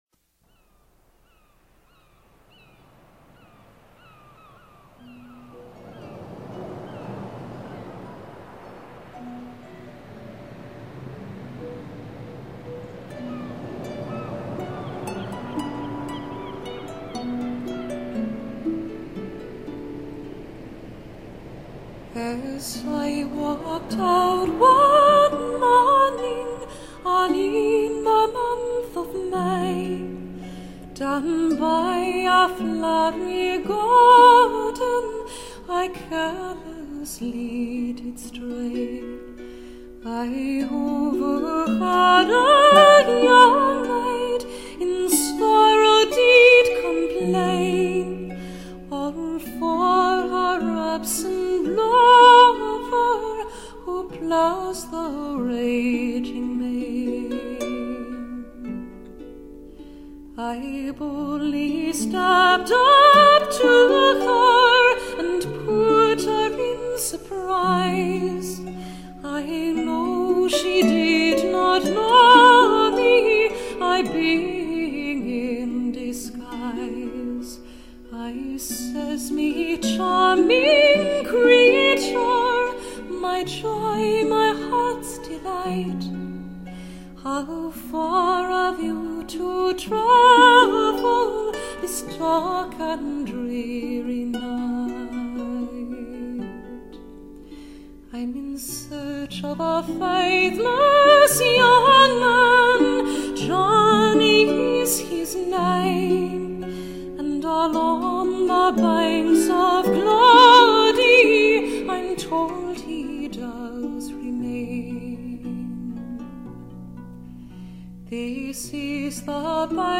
音乐类别：爵士人声
无论全碟音乐的编排，环境氛围的烘托，小鸟等自然声音的渲染，更有和男子的合唱，
一切的一切，目的就是为了表现凯尔特音乐特有的旋律和味道。